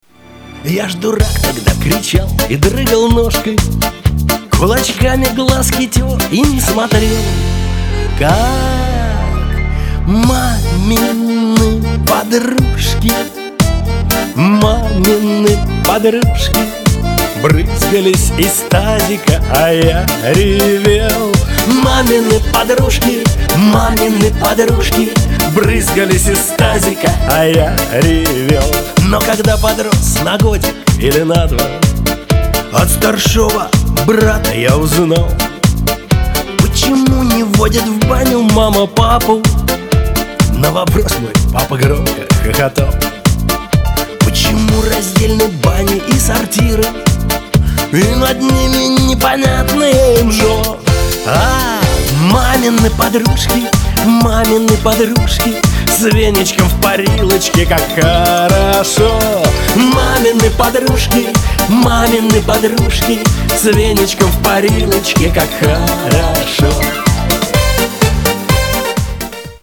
• Качество: 320, Stereo
русский шансон
шуточные